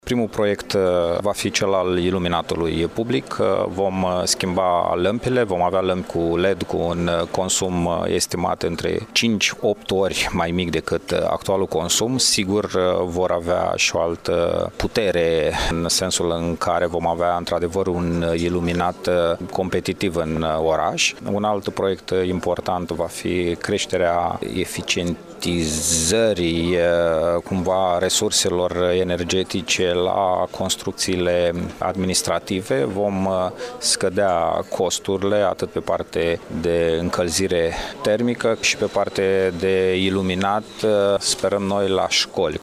Prezent la manifestări, viceprimarul Radu Botez a declarat că la Iași, în următoarea perioadă, vor începe două proiecte care vizează creșterea eficienței energetice.